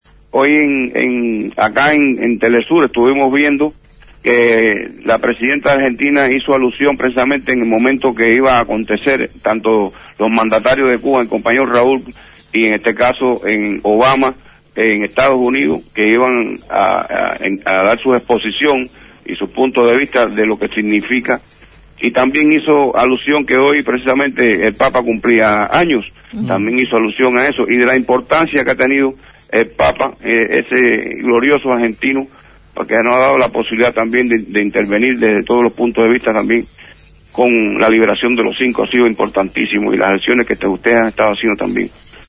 integrante del Comité por la Liberación de los Cinco de la provincia de Villa Clara en el centro de la isla caribeña fue entrevistado en Abramos la Boca.